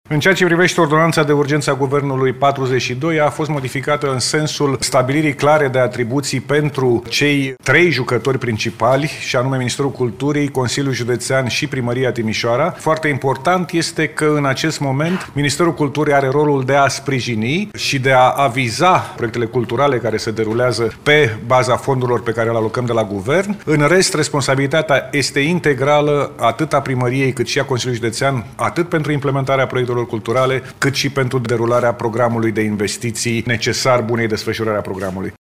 Ministrul Culturii, Lucian Romașcanu, a explicat la finalul ședinței de guvern în care a fost aprobata finanțarea, care este raspunderea autorităților locale în acest proiect.